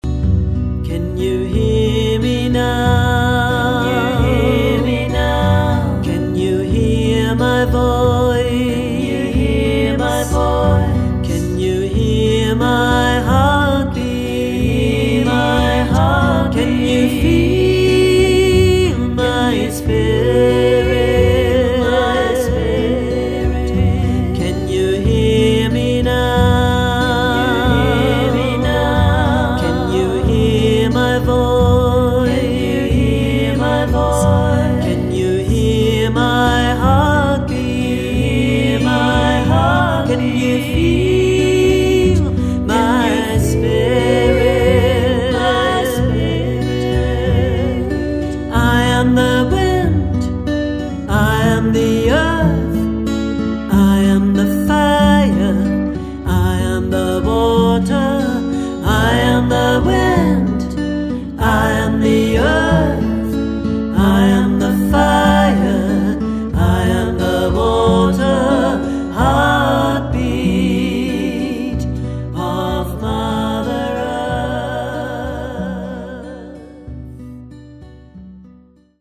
Schwungvolle, meditative  und einfuehlsame Lieder und Gebete